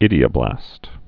(ĭdē-ə-blăst)